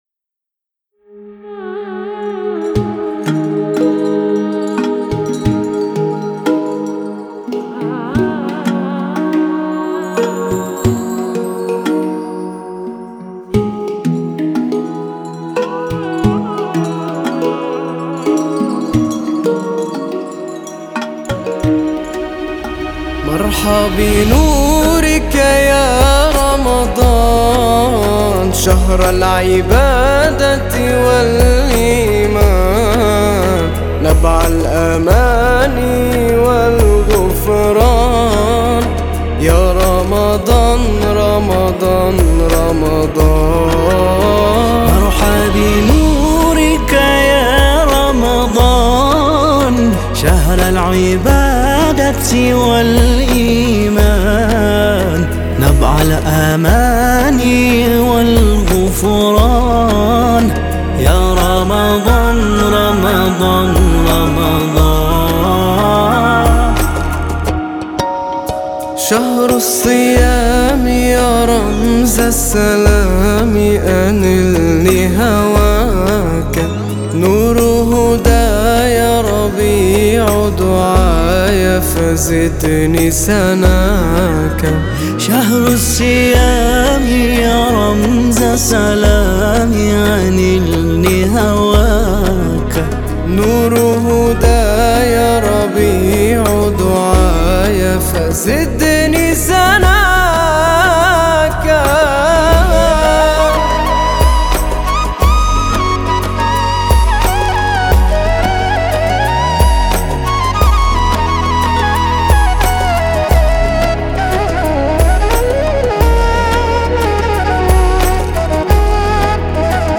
نماهنگ عربی زیبای
با نوای دلنشین